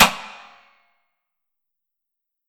Belly Slap
BA-BellySlap-Both-Hands-Hard.wav